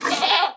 Minecraft Version Minecraft Version latest Latest Release | Latest Snapshot latest / assets / minecraft / sounds / mob / goat / screaming_milk1.ogg Compare With Compare With Latest Release | Latest Snapshot
screaming_milk1.ogg